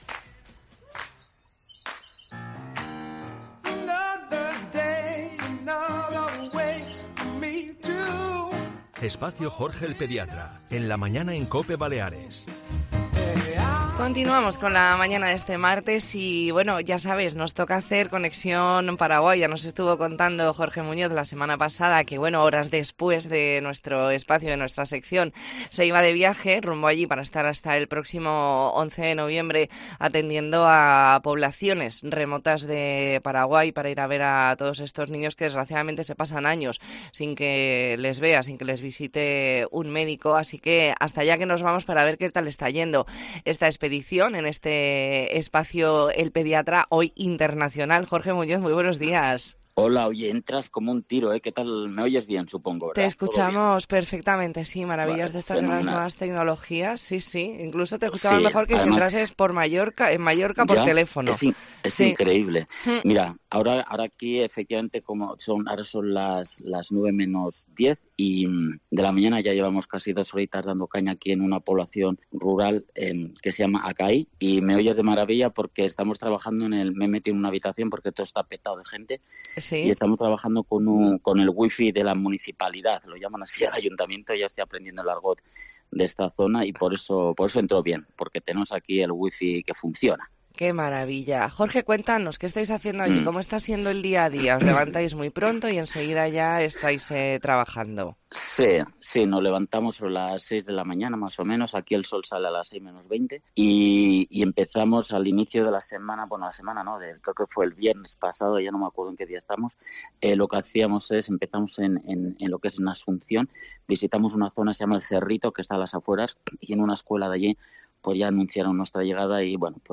Entrevista en La Mañana en COPE Más Mallorca, martes 7 de noviembre de 2023.